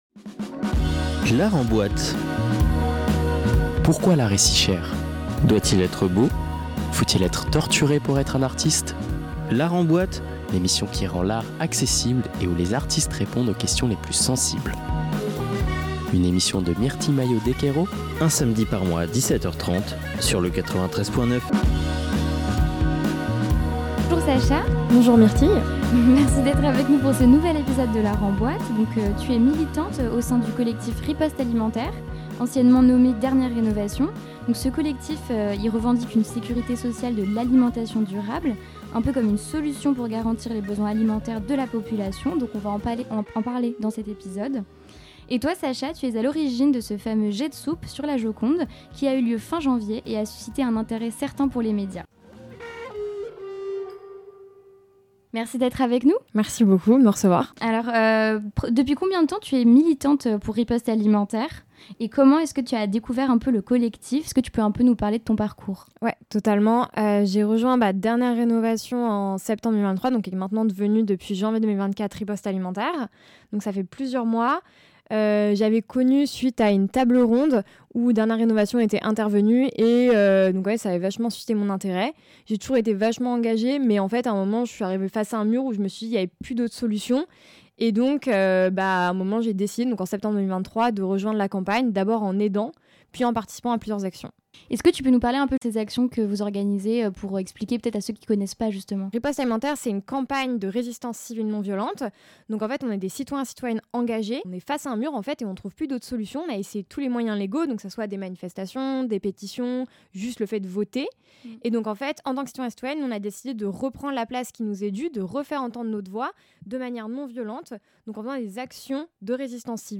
Dans et épisode, nous l'interrogeons sur l'action du 29 janvier dernier, et de ce jet de soupe sur la célèbre Joconde du musée du Louvre.